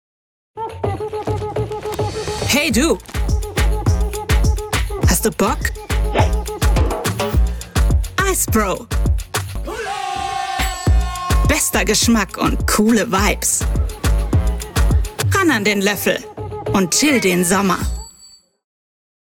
Werbung – rotzig, jung
mitteltief ⋅ frisch ⋅ facettenreich
Werbung_IceBro.mp3